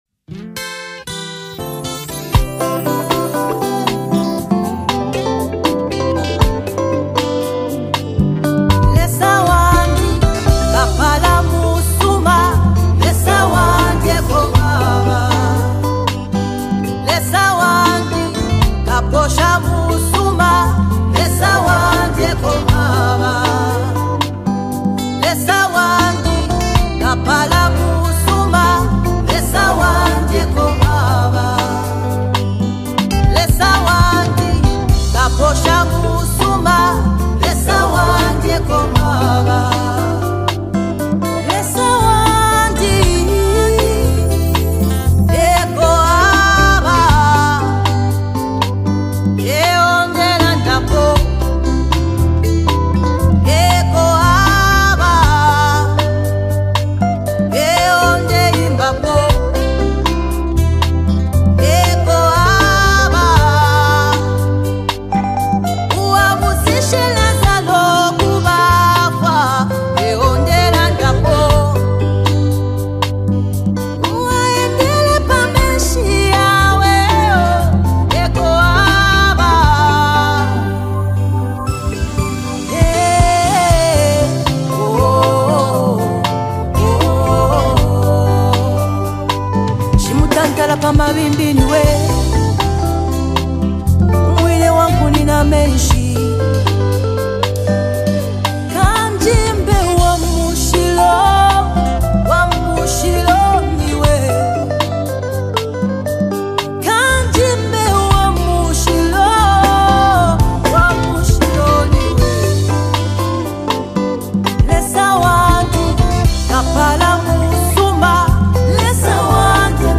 one of Zambia’s top gospel artists
delivery is both passionate and inspiring